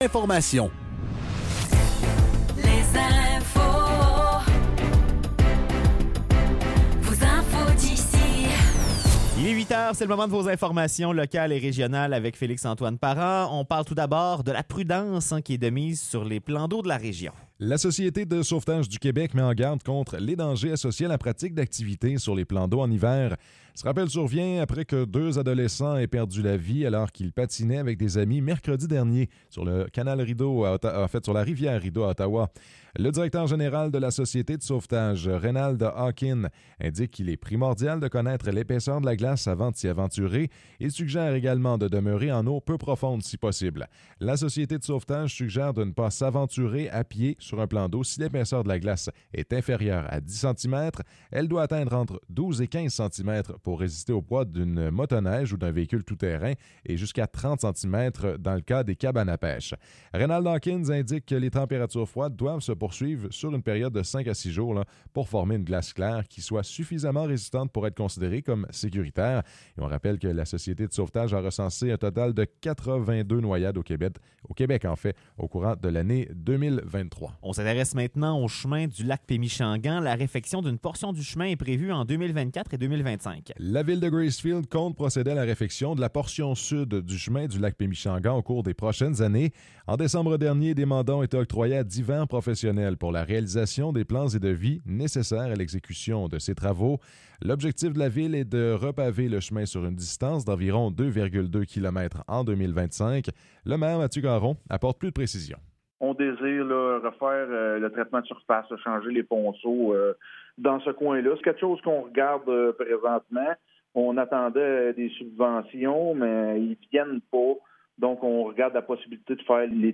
Nouvelles locales - 4 janvier 2024 - 8 h